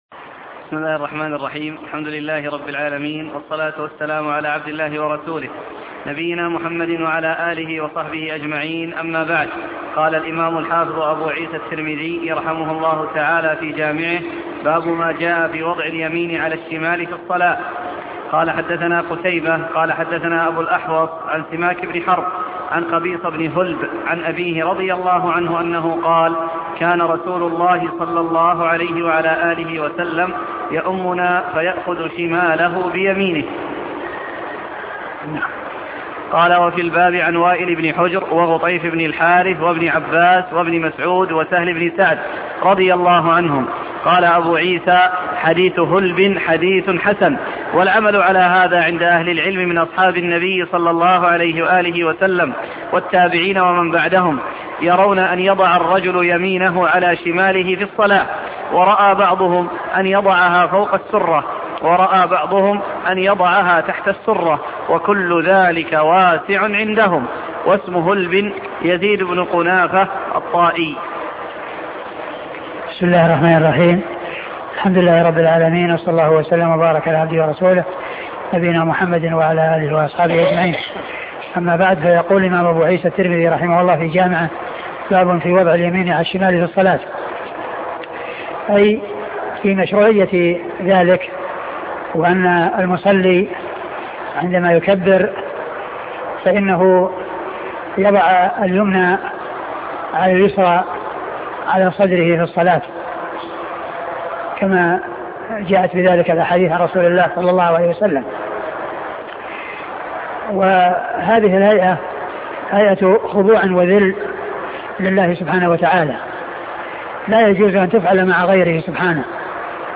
سنن الترمذي شرح الشيخ عبد المحسن بن حمد العباد الدرس 44